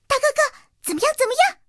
T-127查看战绩语音.OGG